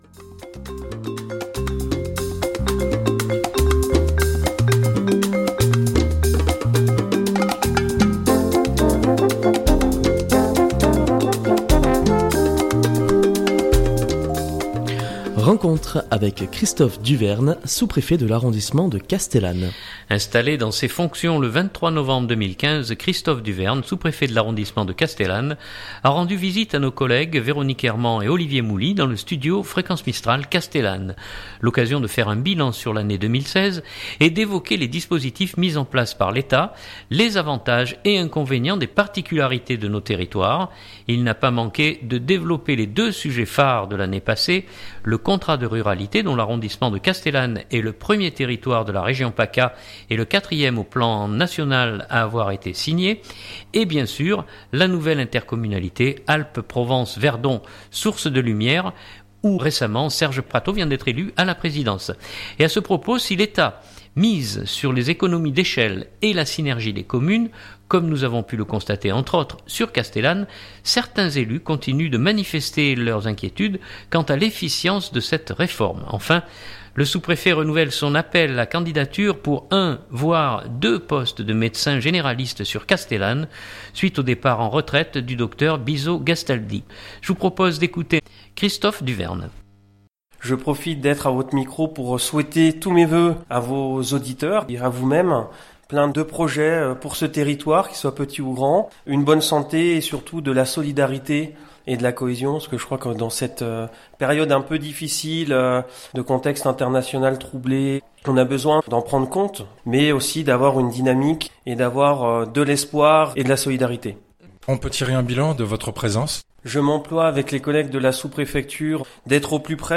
Rencontre avec Christophe Duverne, sous-Préfet de l’arrondissement de Castellane